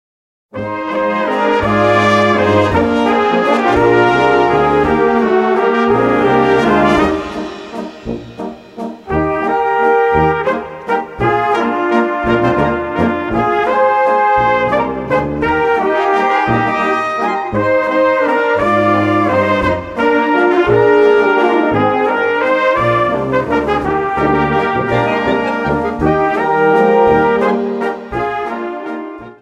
Gattung: Walzer
A-B Besetzung: Blasorchester Zu hören auf